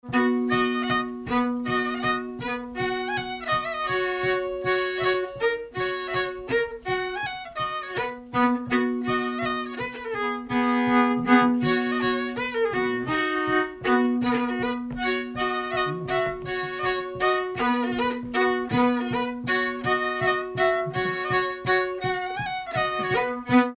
Mazurka
danse : mazurka
circonstance : bal, dancerie
Pièce musicale inédite